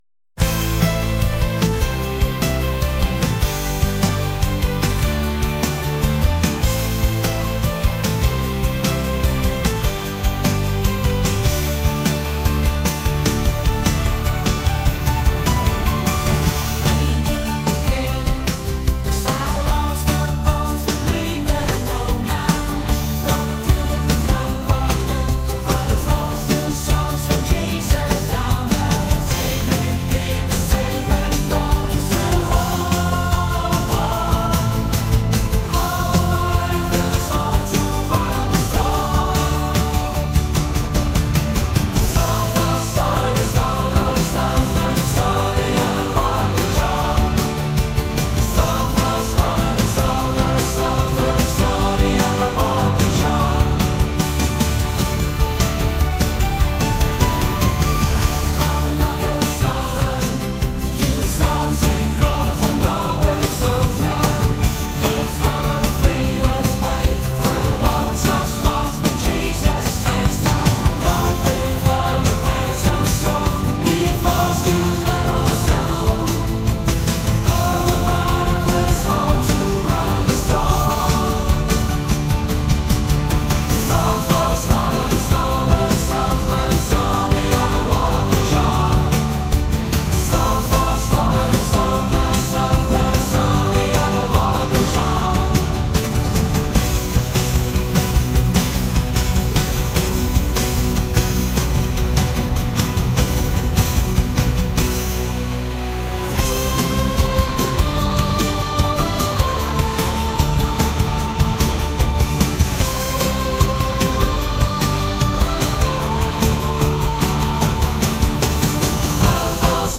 pop | cinematic | soul & rnb